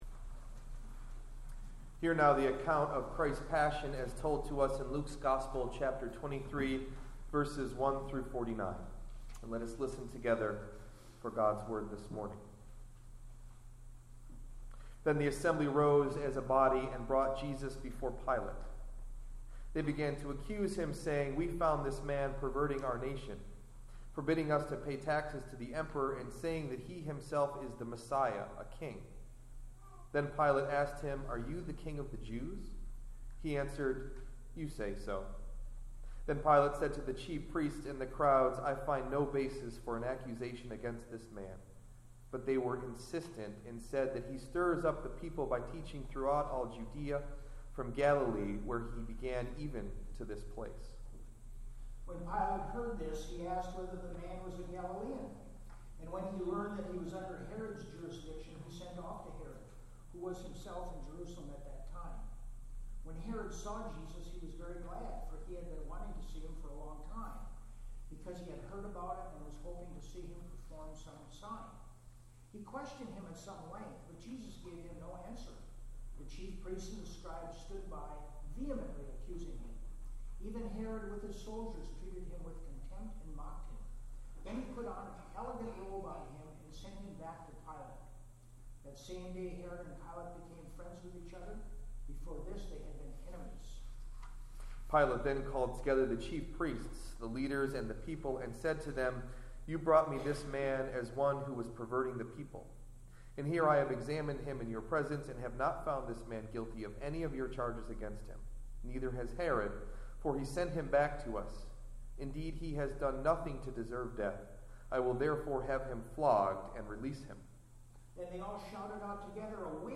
Sermon: “A Passionate Invitation”
Delivered at: The United Church of Underhill